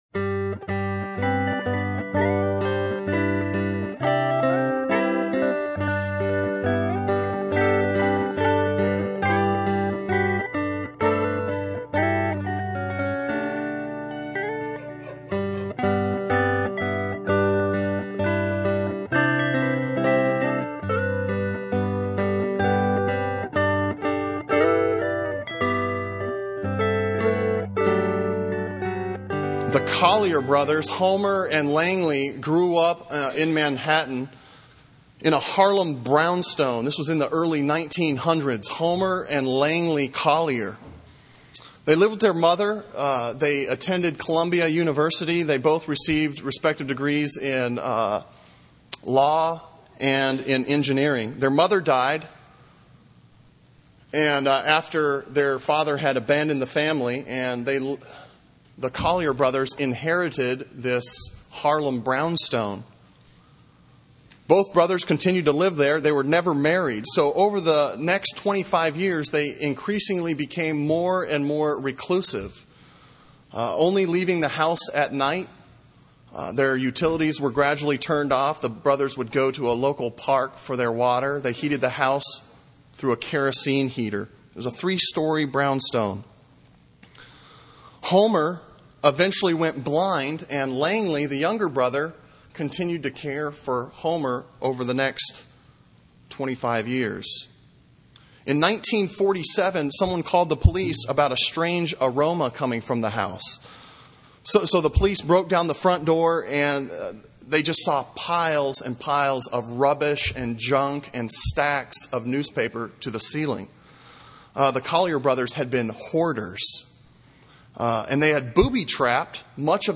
Sermon on Ephesians 3:7-13 from October 14